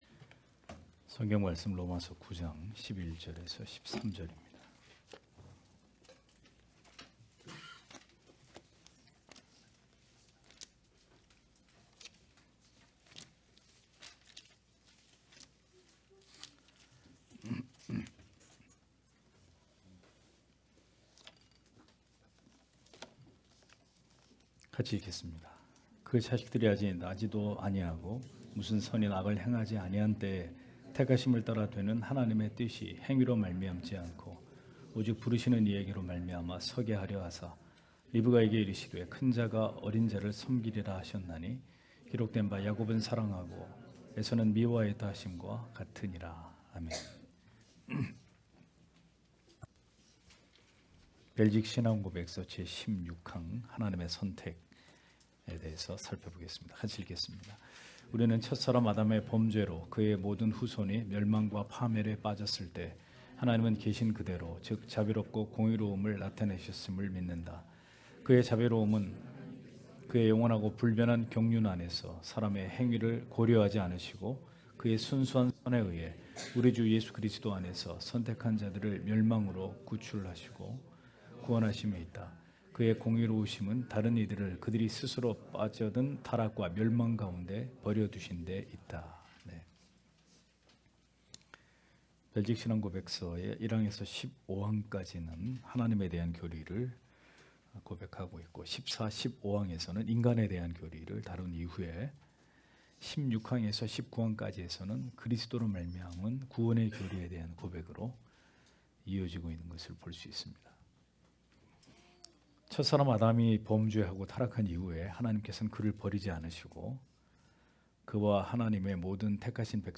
주일오후예배 - [벨직 신앙고백서 해설 17] 제16항 하나님의 선택 (롬 9장11-13절)